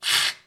electric screwdriver with wooden place resonance.wav
electric screwdriver working with prefabricated wood plates
electric_screwdriver_with_wooden_place_resonance_lfj.mp3